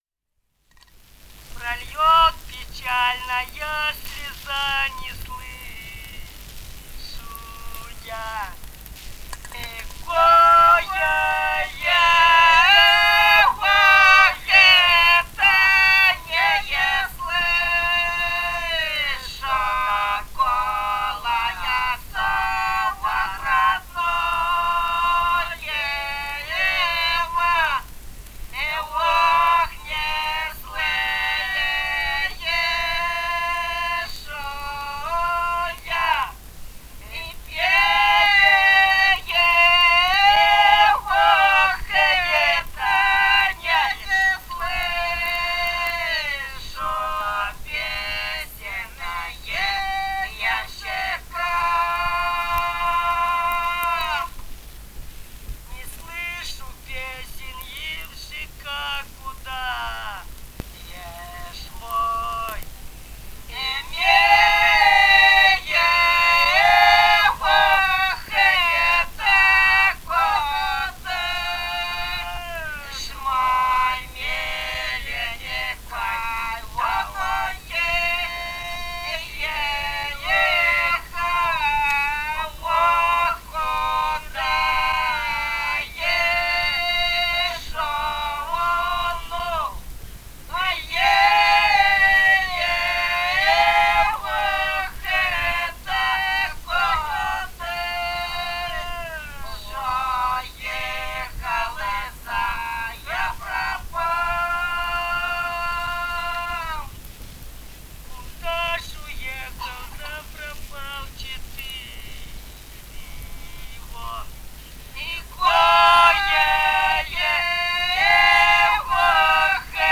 Этномузыкологические исследования и полевые материалы
«Прольёт печальная слеза» (лирическая).
Бурятия, с. Желтура Джидинского района, 1966 г. И0904-11